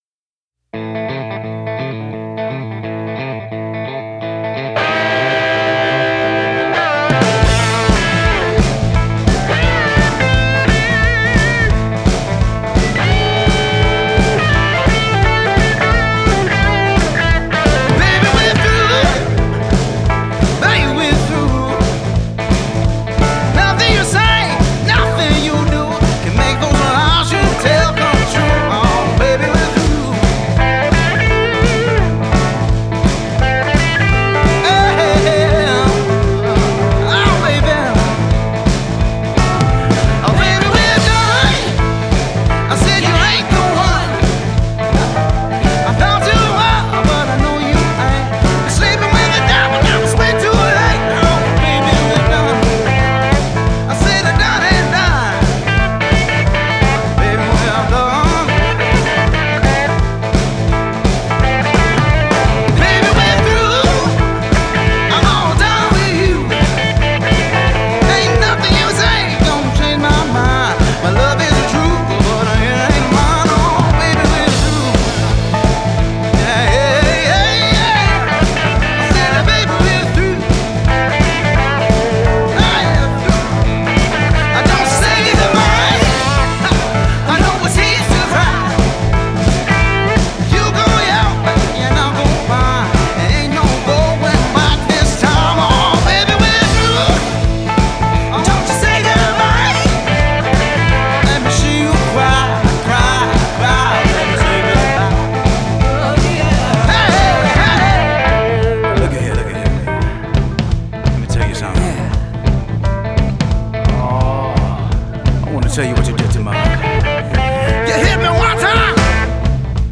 blues-rock band
is a hard-driving, one-chord boogie
vocals
guitar
bass
drums
keys